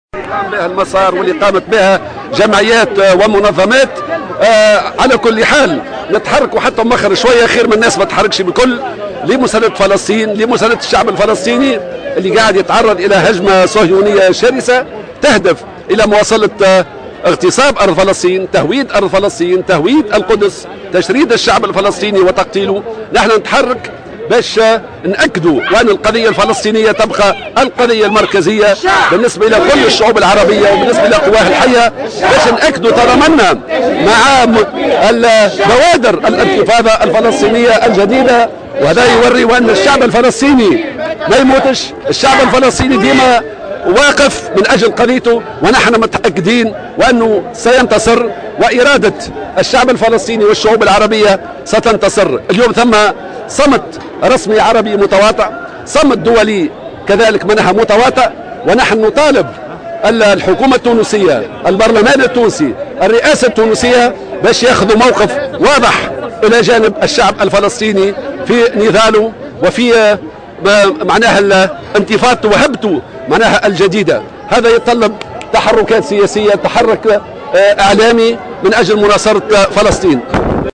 حمة الهمامي خلال مسيرة بالعاصمة : على السلطات التونسية أن تعلن بوضوح تأييدها للفلسطينيين
قال الناطق الرسمي باسم الجبهة الشعبية، حمة الهمامي، في تصريح لمراسلة الجوهرة أف أم اليوم السبت، خلال مسيرة في شارع الحبيب بورقيبة بالعاصمة، للتنديد بالممارسات الإسرائيلية ضد الفلسطينيين، إن هذا التحرك ورغم كونه جاء متأخرا إلا أنه يبقى أفضل من أطراف أخرى لم تتحرك لمساندة فلسطين وشعبها في وجه الهجمة الصهيونية الشرسة.